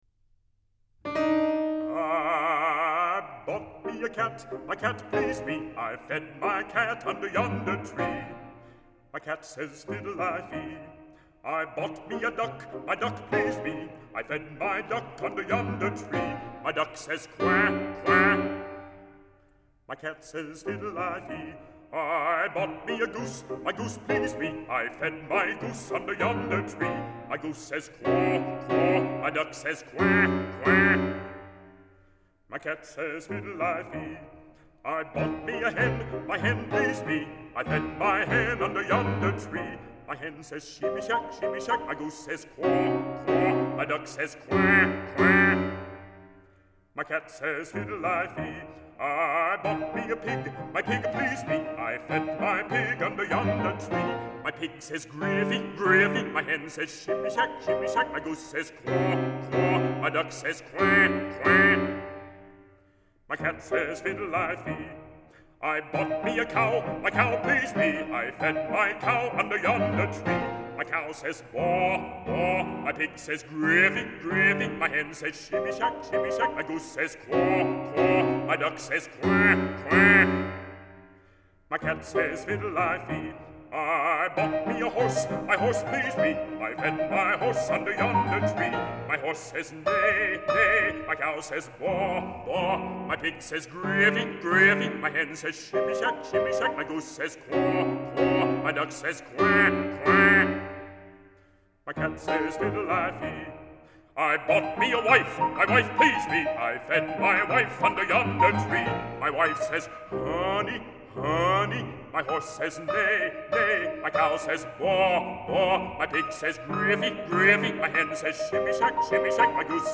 Música vocal
Canto